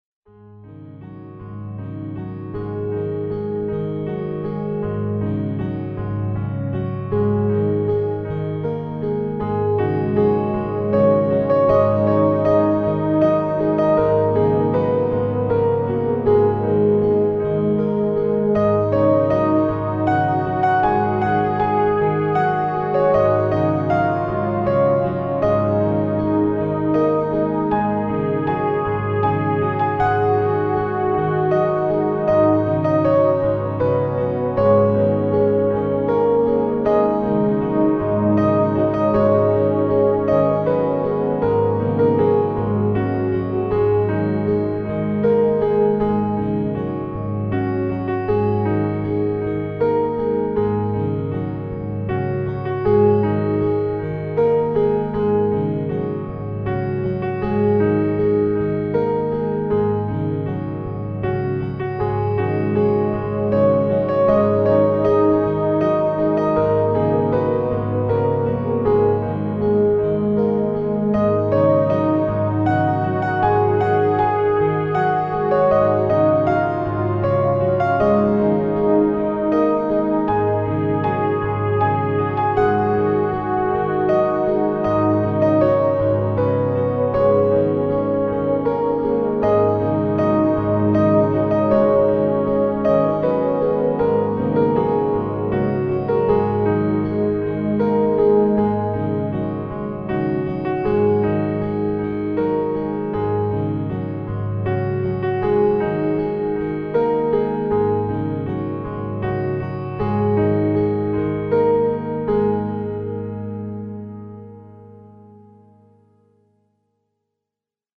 Traditional Scale.
Piano (D#m) - 'And no singing!'